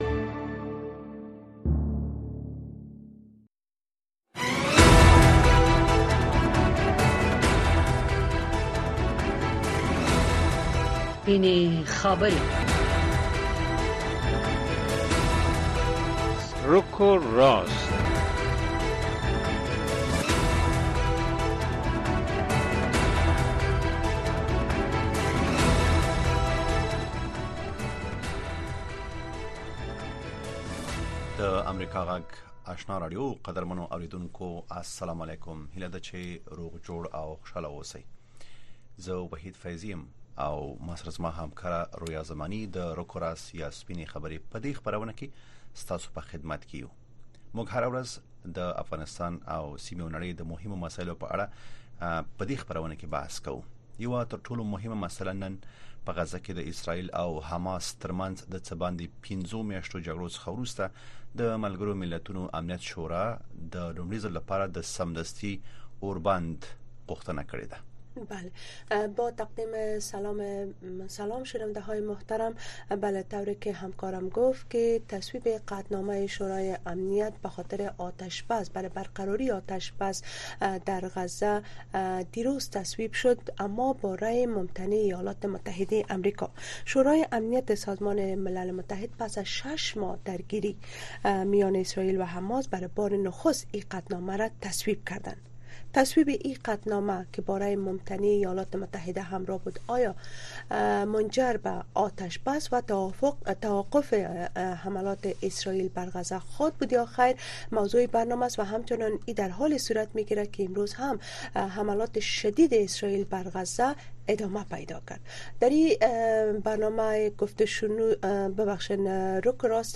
در برنامۀ رک و راست بحث‌های داغ صاحب‌نظران و تحلیلگران را در مورد رویدادهای داغ روز در افغانستان دنبال کرده می‌توانید. این برنامه زنده به گونۀ مشترک به زبان‌های دری و پشتو هر شب از ساعت هشت تا نه شب به وقت افغانستان پخش می‌شود.